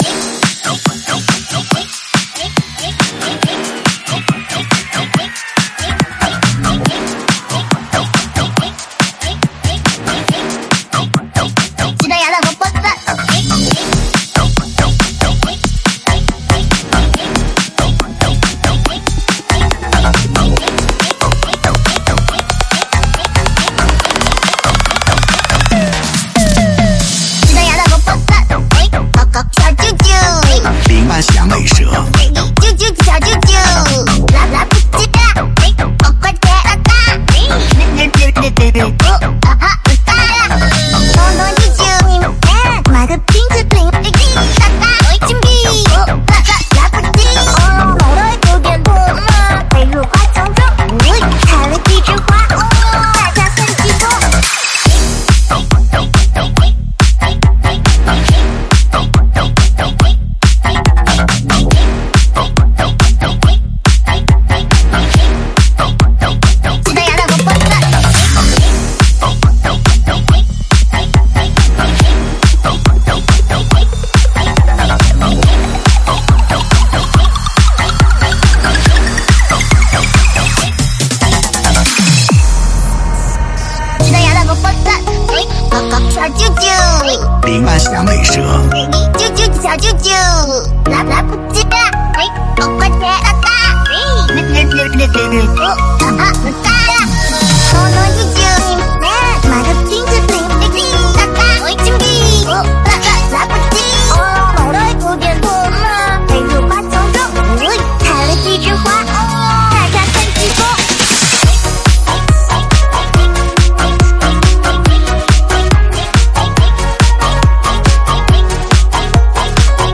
试听文件为低音质，下载后为无水印高音质文件 M币 10 超级会员 M币 5 购买下载 您当前未登录！